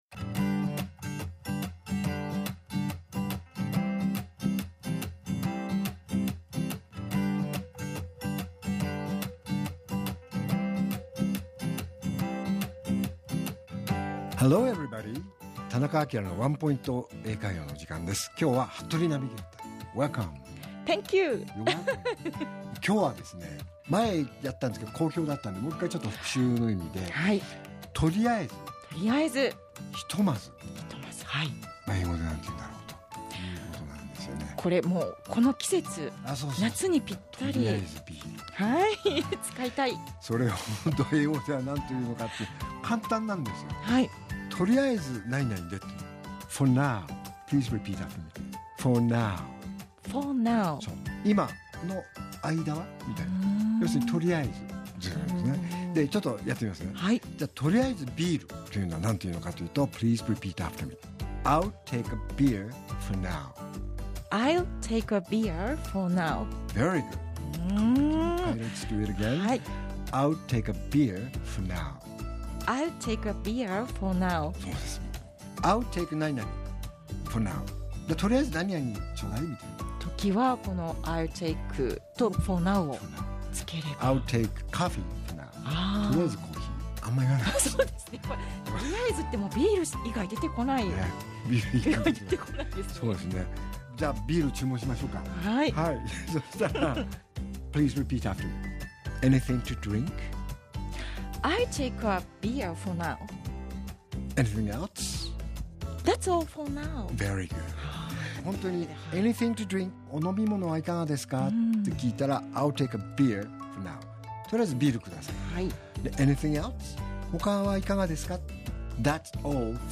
R7.8 AKILA市長のワンポイント英会話